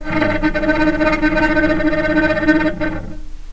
vc_trm-D#4-pp.aif